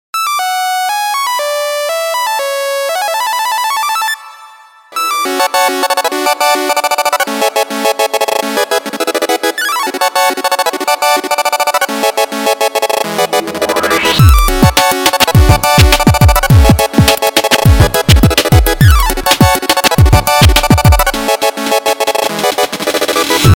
Нарезка на звонок.